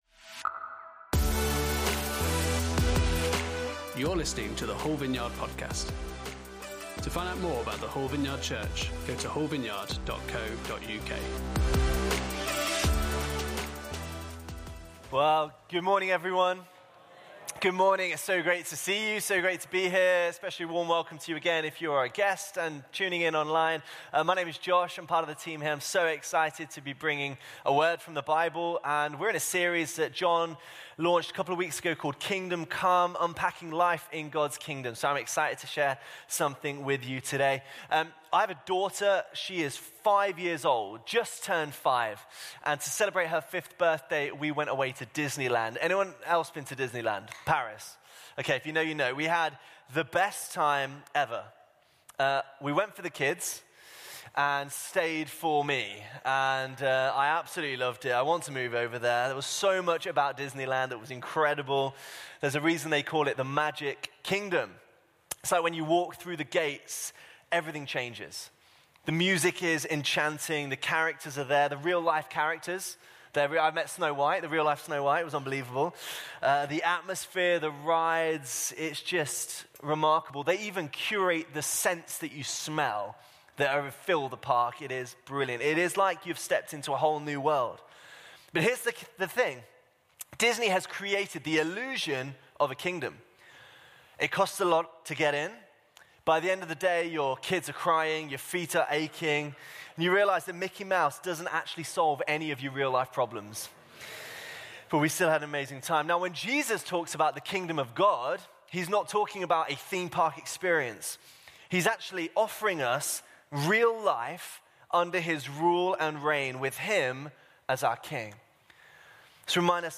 Series: Kingdom Come Service Type: Sunday Service On Sunday morning